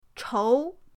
chou2.mp3